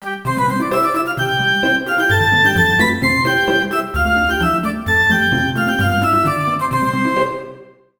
Título Tonalidad de Do mayor. Ejemplo.